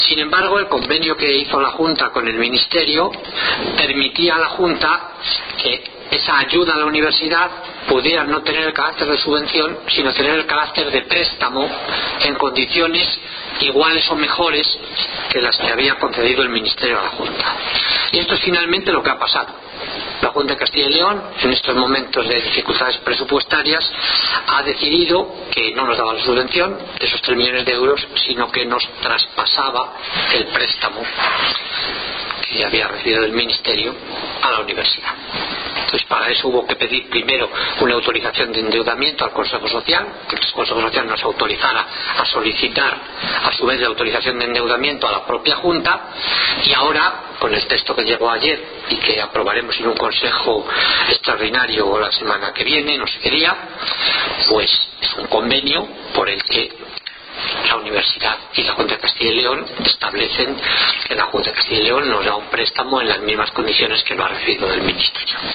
Declaraciones de Daniel Hernández Ruipérez durante la rueda de prensa sobre la sesión ordinaria del Consejo de Gobierno del mes de junio